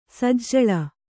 ♪ sajjaḷa